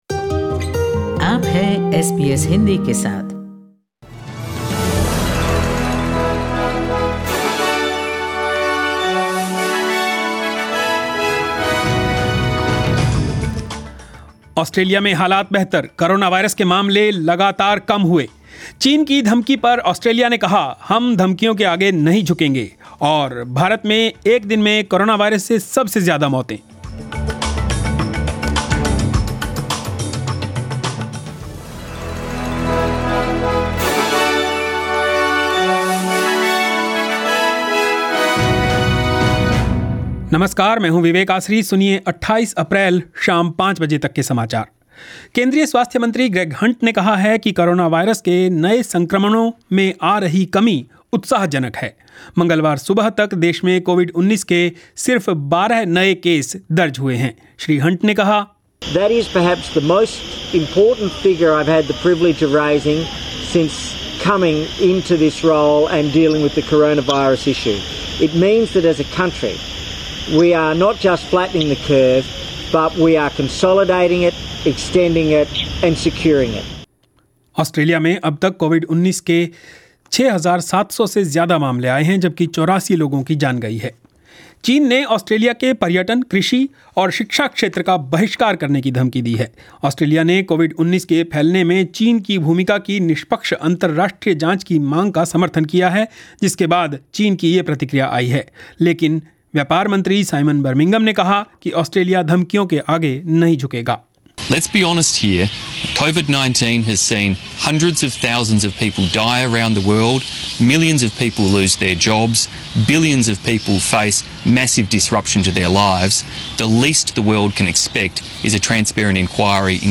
News in Hindi 28 March 2020